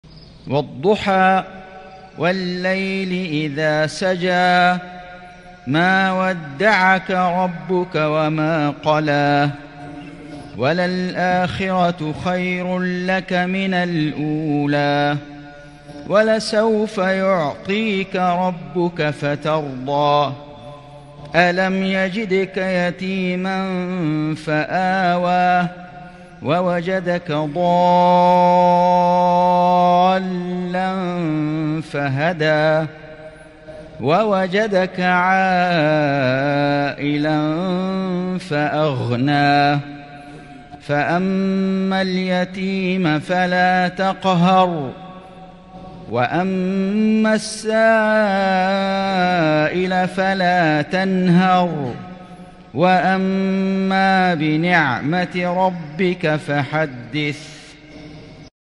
سورة الضحى > السور المكتملة للشيخ فيصل غزاوي من الحرم المكي 🕋 > السور المكتملة 🕋 > المزيد - تلاوات الحرمين